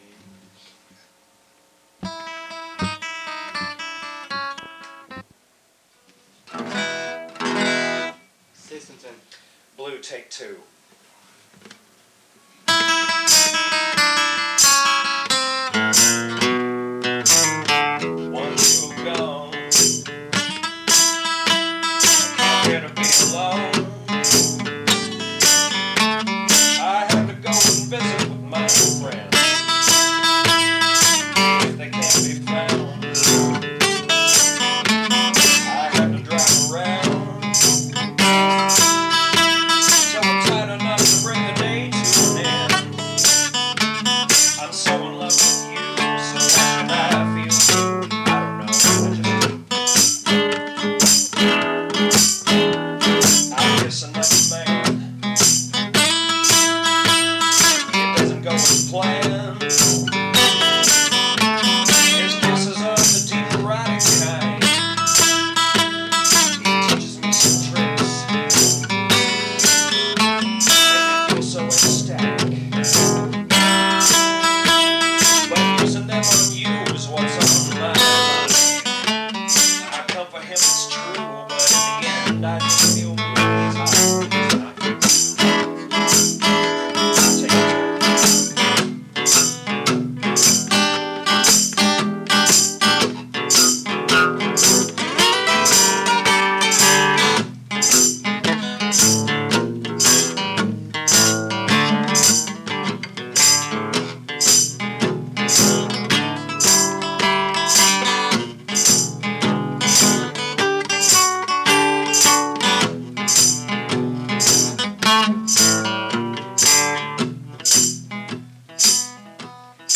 Vocals and Tamborine
Guitar